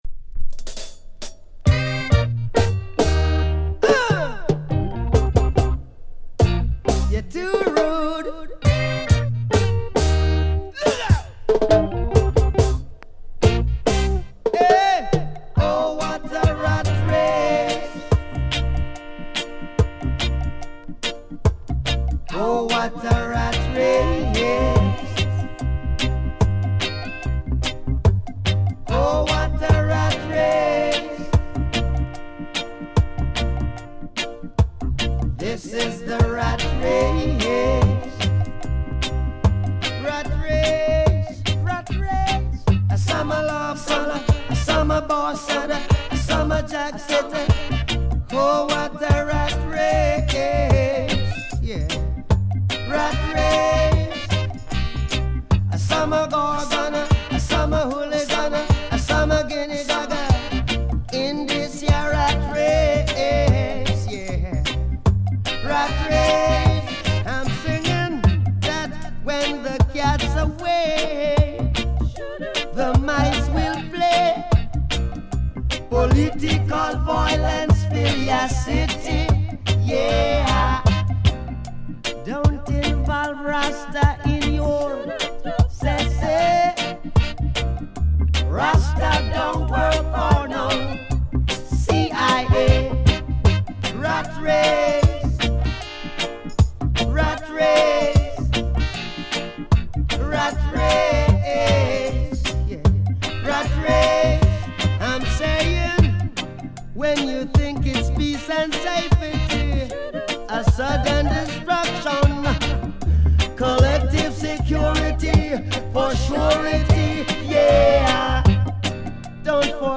Derjenige der Reggae nicht mag soll woanders surfen.